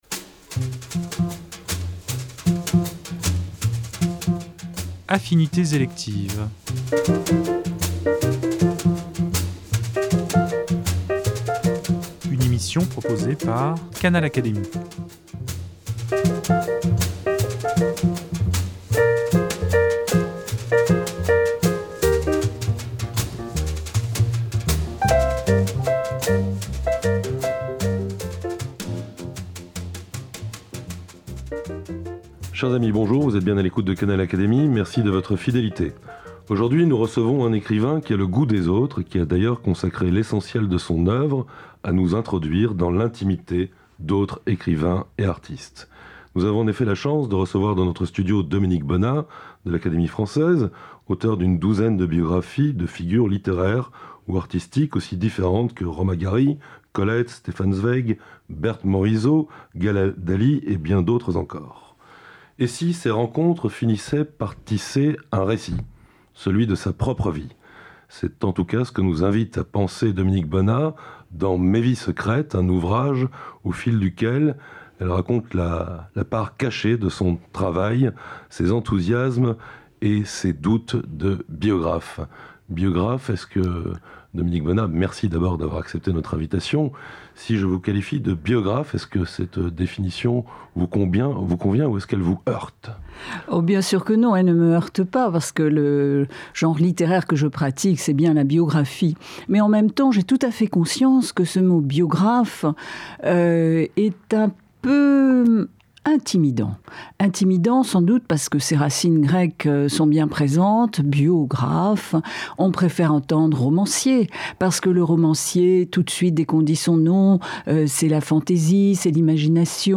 Au fil des pages et de l’entretien qu’elle nous a accordé, on croise bien sûr les artistes et écrivains dont elle a raconté la vie : Romain Gary, Stephan Zweig, Gala Dali, Camille Claudel, sans oublier sa chère Colette.